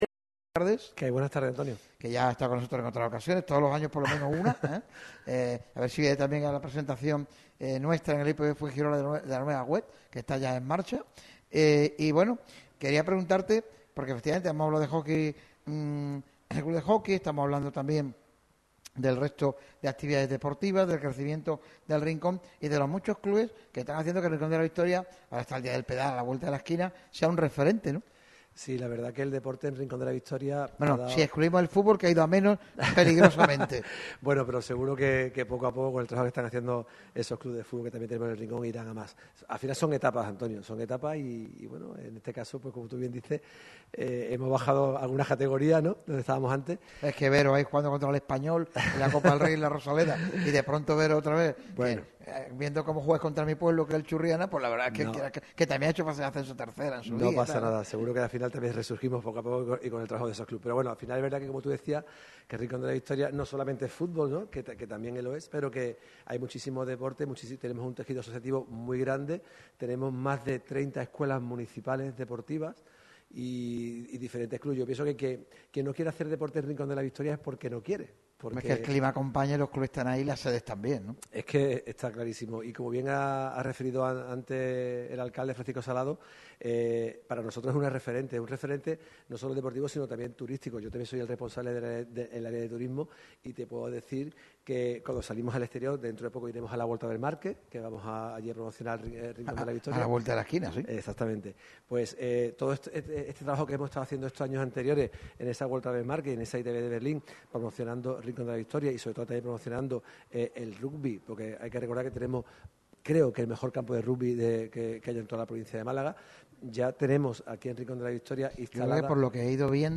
La radio del deporte ha llevado a cabo un programa especial en el museo arqueológico de Villa Antiopa.
Radio MARCA Málaga se ha desplazado al museo arqueológico de Villa Antiopa en Torre de Benagalbón (Rincón de la Victoria). Varios representantes del Ayuntamiento del municipio no han desaprovechado la oportunidad de pasarse por la sintonía del 96.8 FM, siempre encontrando la relación entre el Rincón de la Victoria y el deporte malagueño.